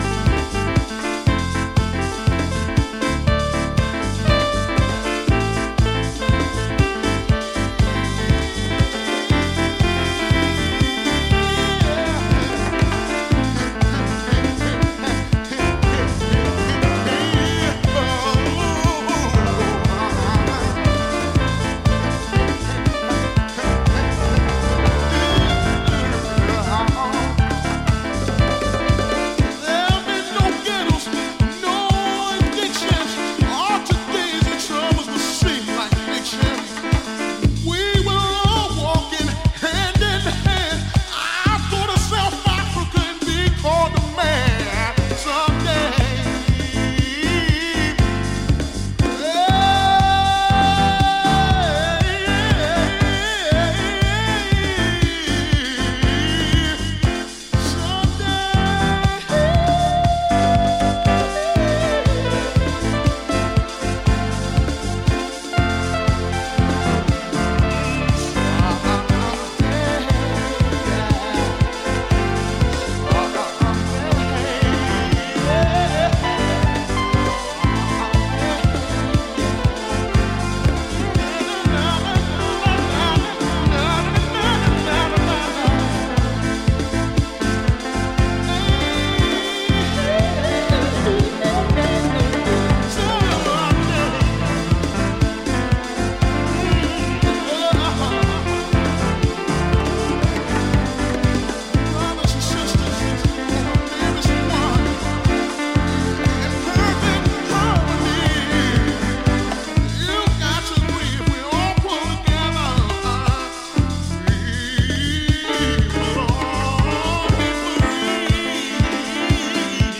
ジャンル(スタイル) CLASSIC HOUSE